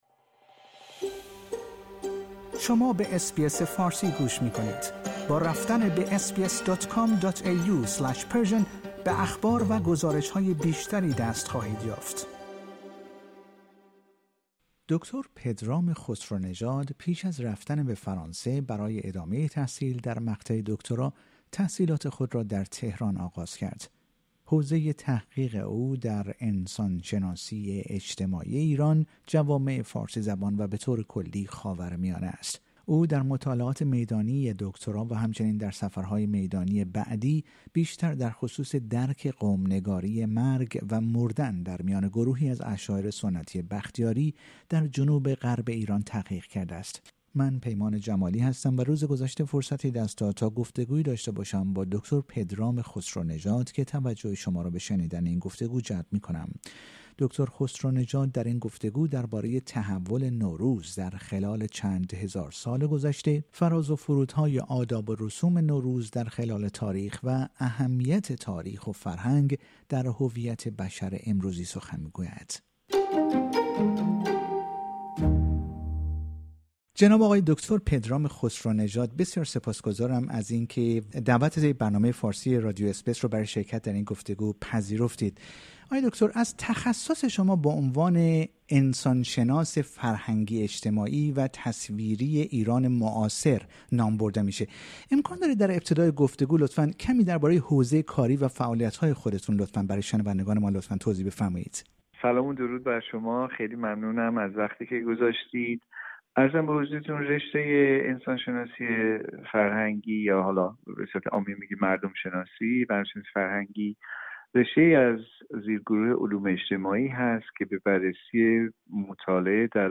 در گفتگویی اختصاصی با رادیو اس بی اس فارسی